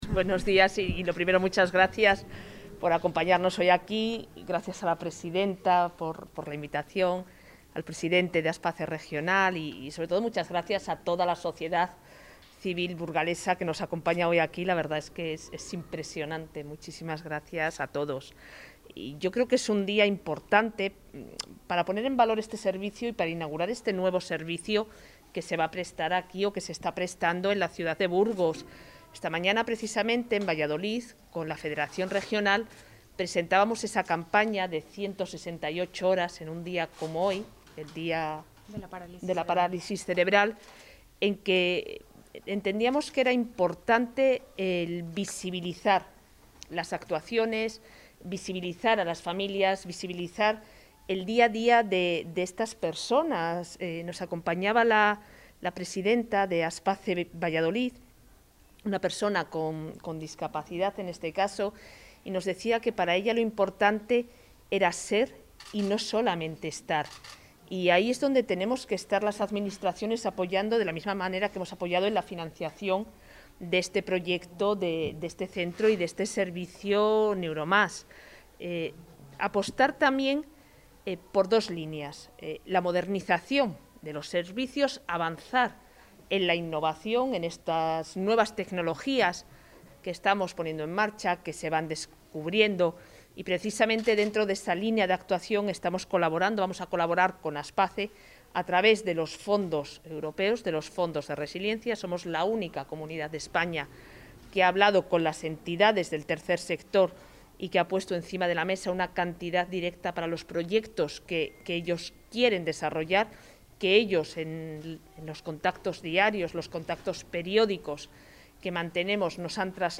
Audio consejera Atención a medios